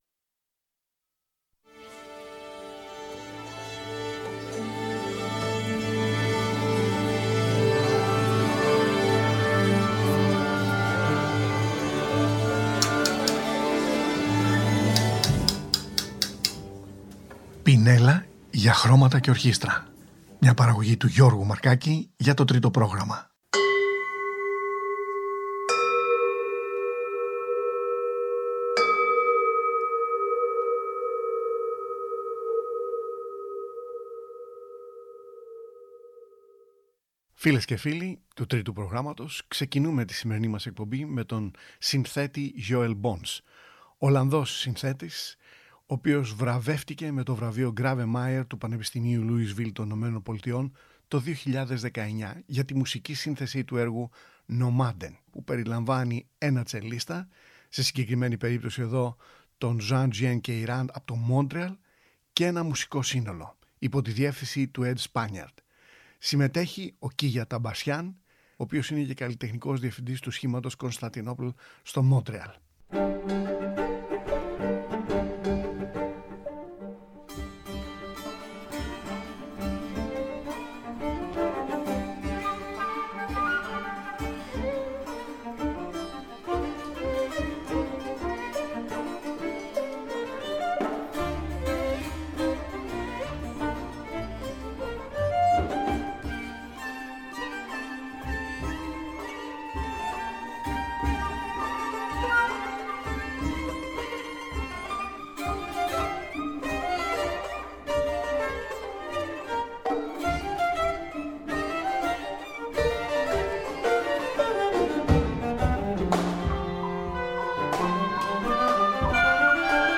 Φλαμανδούς